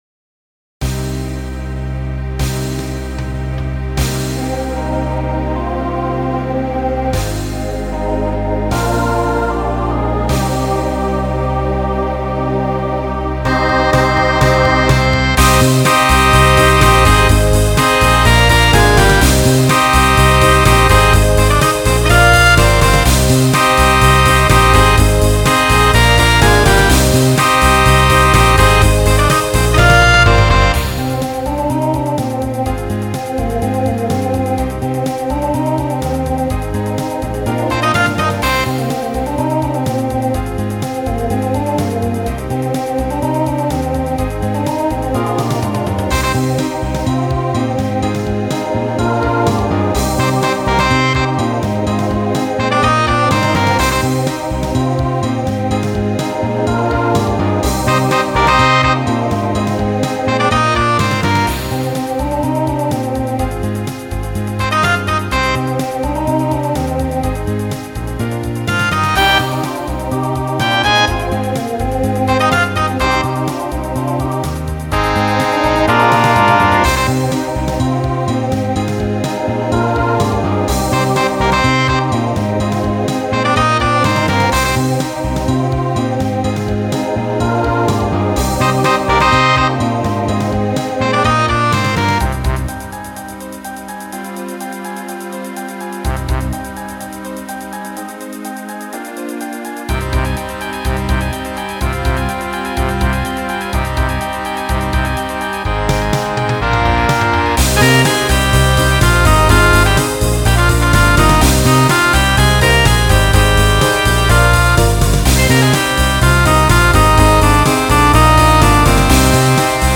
New TTB voicing for 2022.